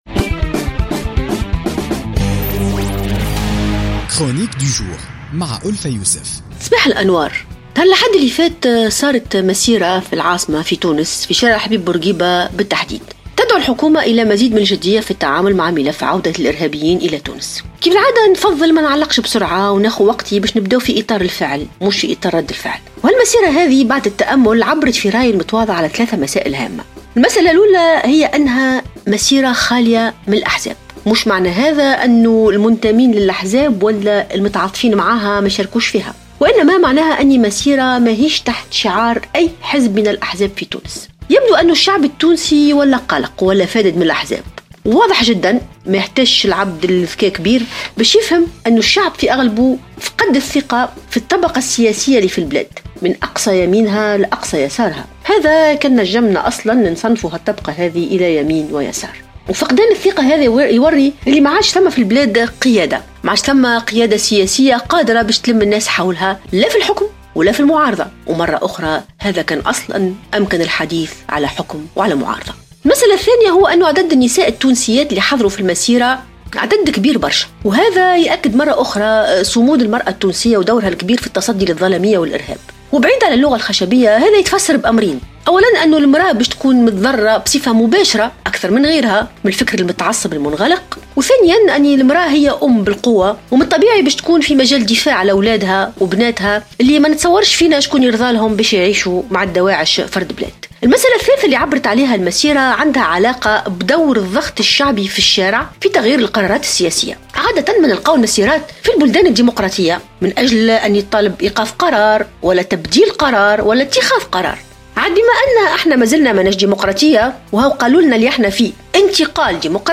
تطرقت الكاتبة ألفة يوسف في افتتاحية اليوم الأربعاء 11 جانفي 2016 إلى المسيرة التي نظمت الأحد الماضي في العاصمة لدعوة الحكومة للتعامل بجدية مع ملف عودة الإرهابيين إلى تونس مشيرة إلى أن المسيرة عبرت عن 3 مسائل هامة .